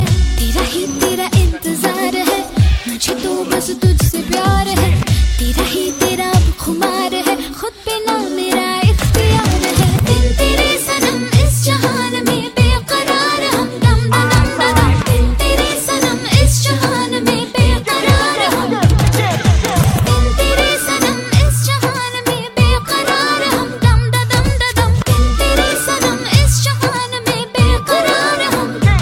• Bollywood Ringtones